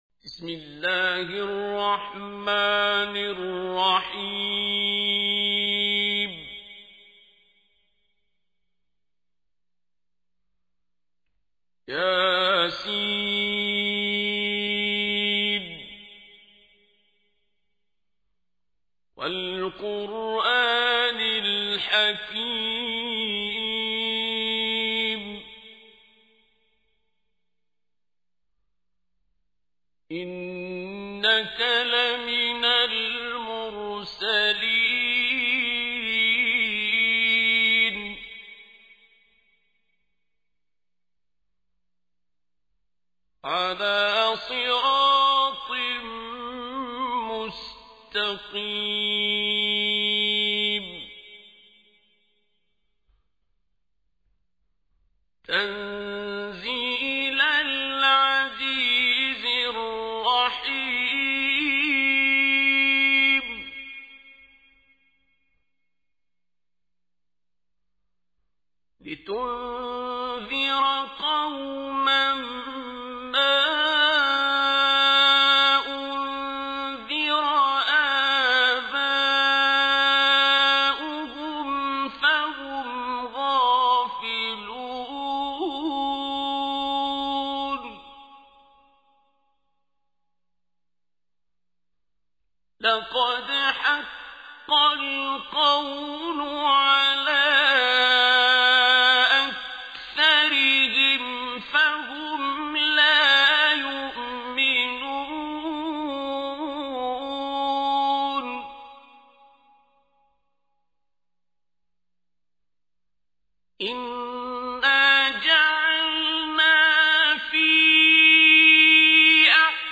تحميل : 36. سورة يس / القارئ عبد الباسط عبد الصمد / القرآن الكريم / موقع يا حسين